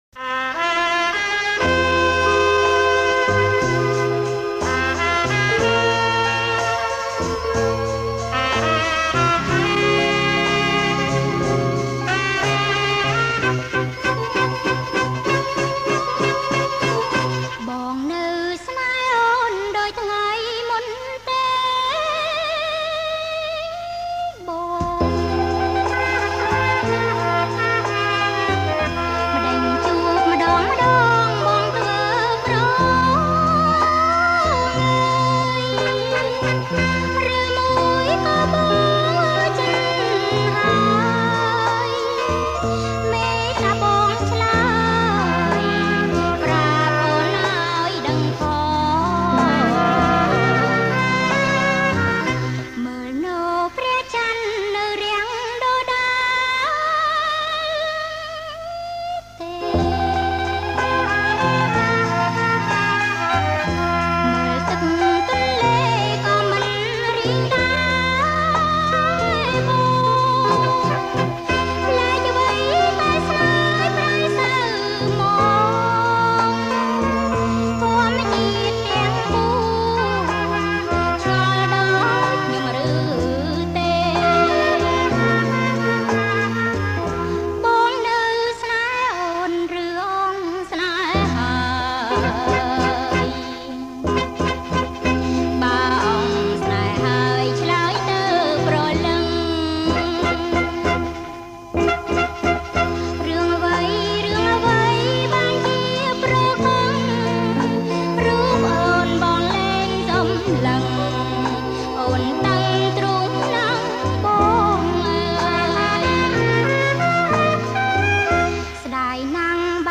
• បទភ្លេងបរទេស
• ប្រគំជាចង្វាក់ Slow Rock